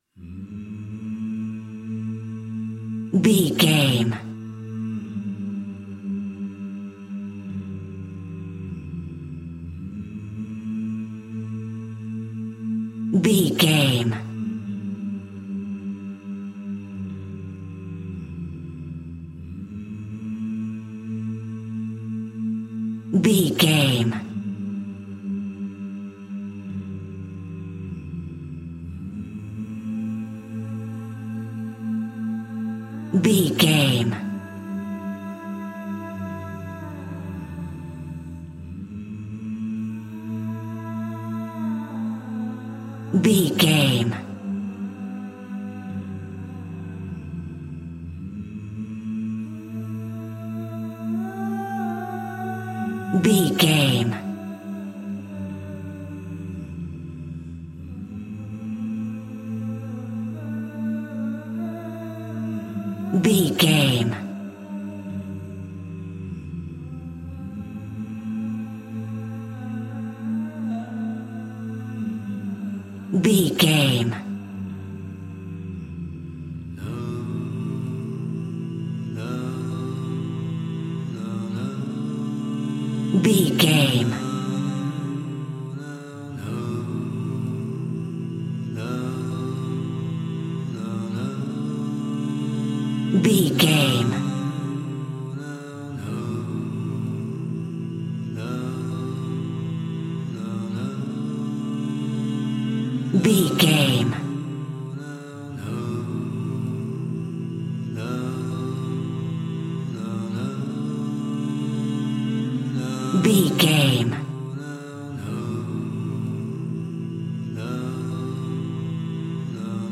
Aeolian/Minor
groovy